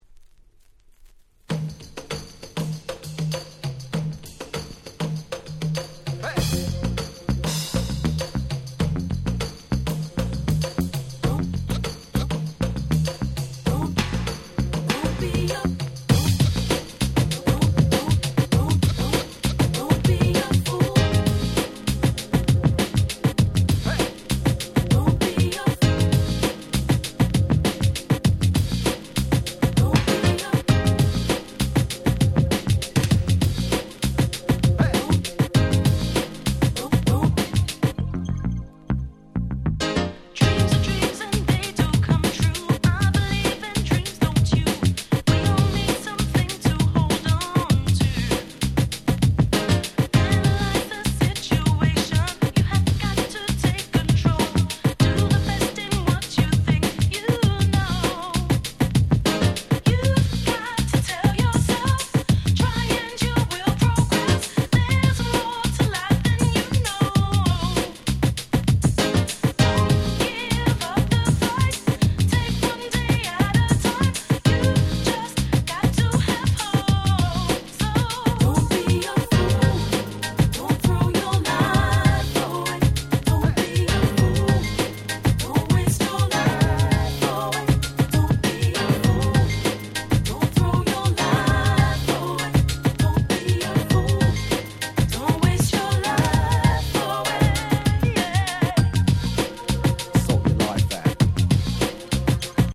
UK Soul Super Classics !!